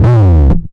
Slide Down (JW2).wav